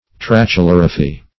Search Result for " trachelorrhaphy" : The Collaborative International Dictionary of English v.0.48: Trachelorrhaphy \Tra`che*lor"rha*phy\, n. [Gr.
trachelorrhaphy.mp3